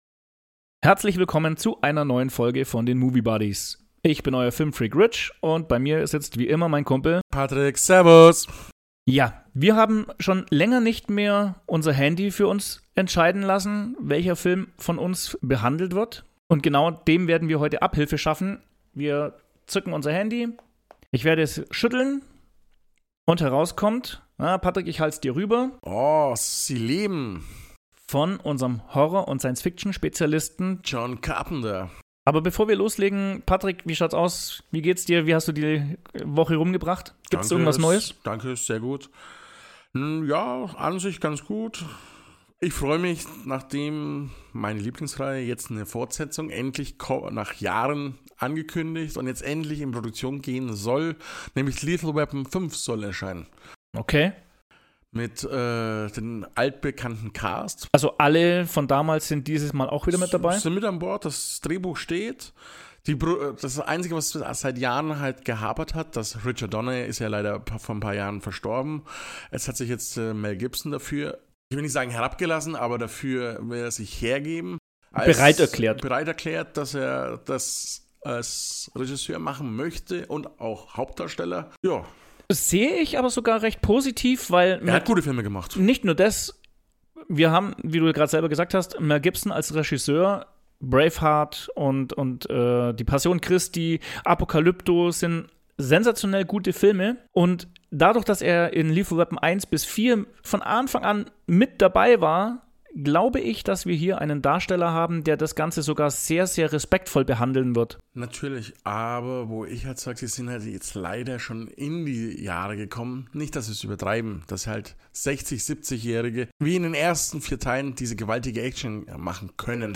Ist der Film nur eine bemerkenswerte Form künstlerischen Ausdrucks, oder ein Streifschuss gegen die stetig anwachsende Konsumgesellschaft und Blindheit der Bevölkerung gegenüber ihrer eigens erschaffenen Versklavung? Nehmt Teil an einer spannenden und informativen Unterhaltung zum Thema Sci-Fi und Realität.